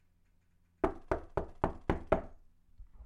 敲门声2
描述：另一个简单的敲门声
声道立体声